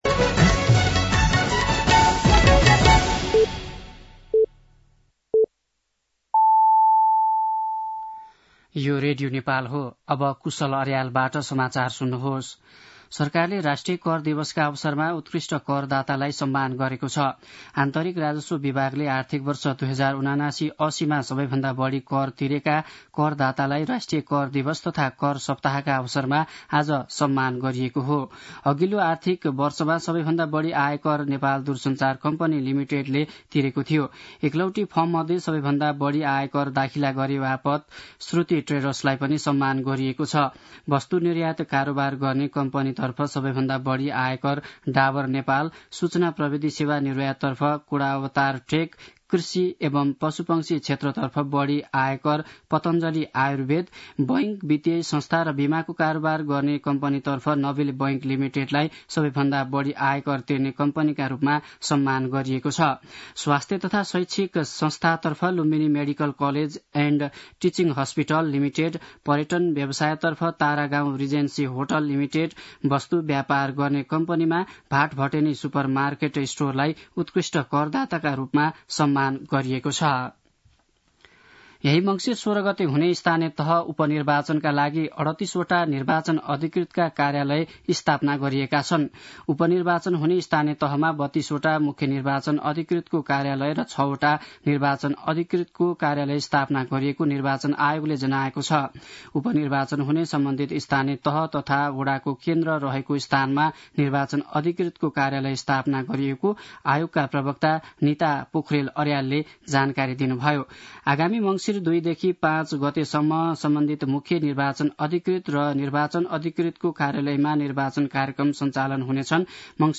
मध्यान्ह १२ बजेको नेपाली समाचार : २ मंसिर , २०८१
12-pm-Nepali-News-2.mp3